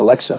synthetic-wakewords
ovos-tts-plugin-deepponies_Donald Trump_en.wav